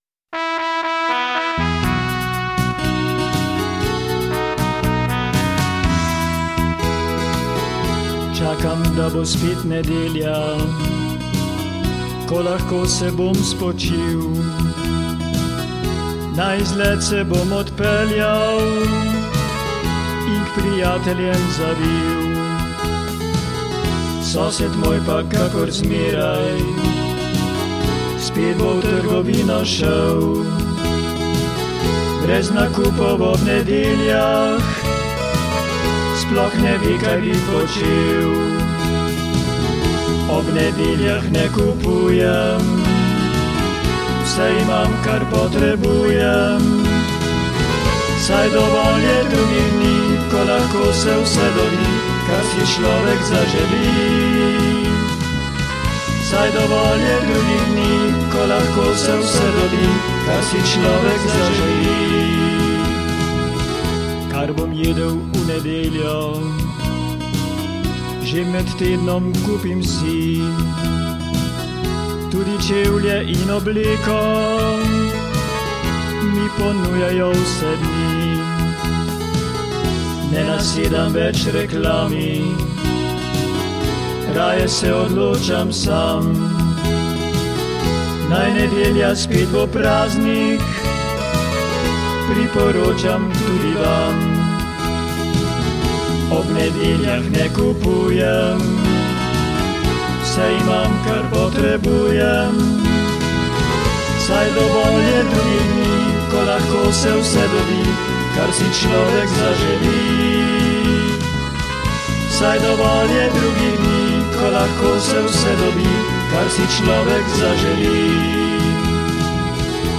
Posnetek  : DEMO 2 (za predstavitev, vaje...)